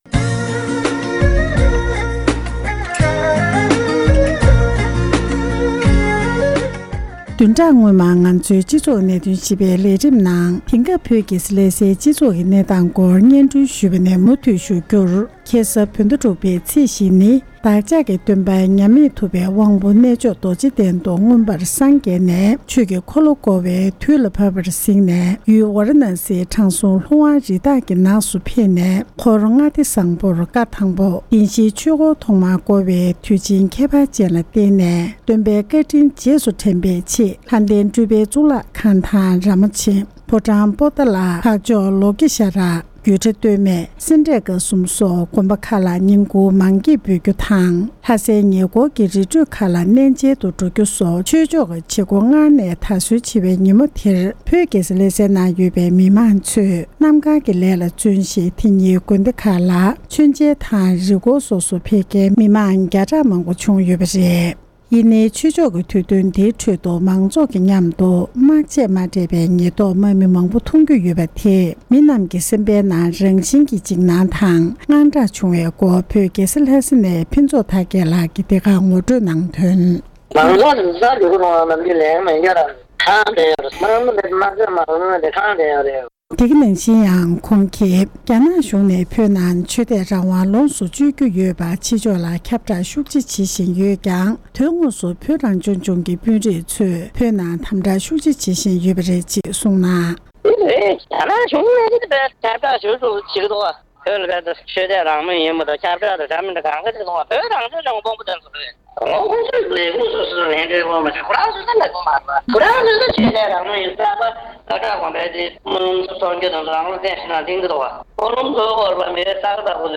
བོད་ནང་གི་བོད་མི་ཞིག་ལ་གནས་འདྲི་ཞུས་སྟེ་གནས་ཚུལ་ཕྱོགས་སྒྲིག་ཞུས་པ་ཞིག་གསན་རོགས་གནང་།།